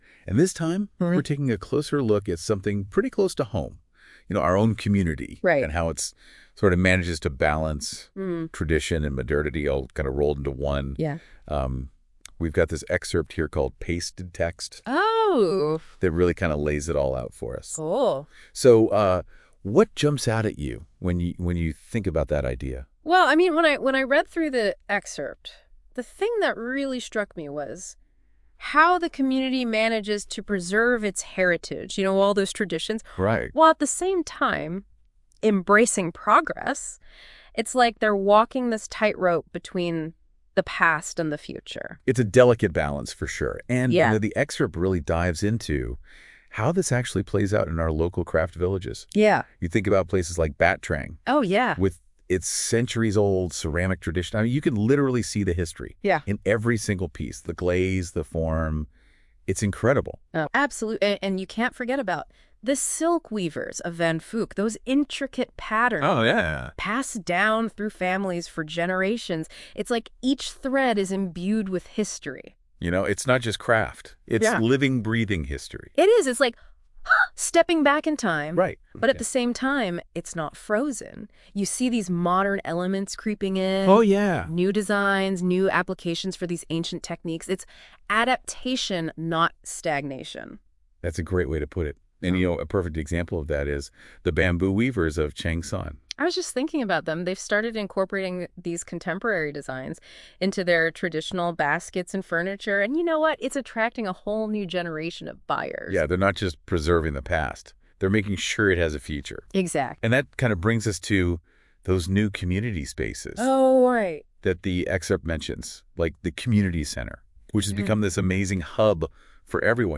Tiếng Anh 9 – Unit 1: Local communication – Dialogue 1